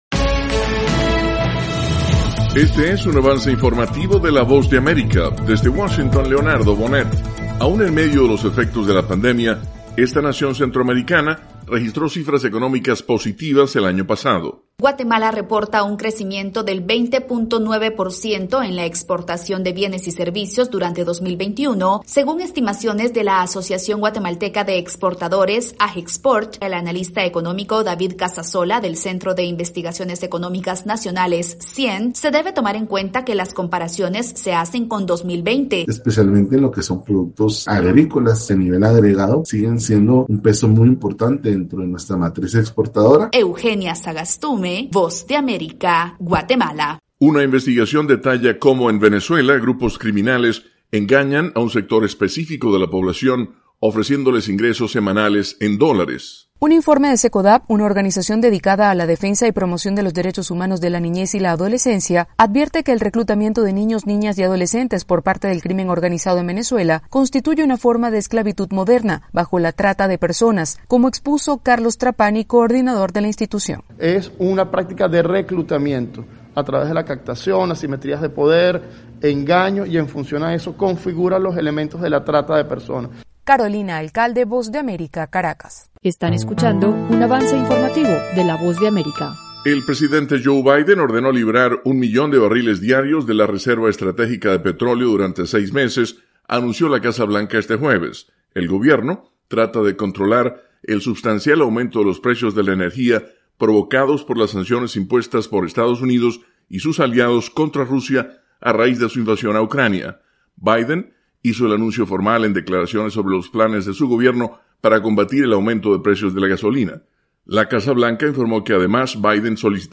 AP - En uno de los auditorios de la Casa Blanca, el presidente Joe Biden habla sobre planes de su administración para combatir el aumento de los precios de la gasolina. 31 marzo 2022.